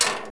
LADDER2.WAV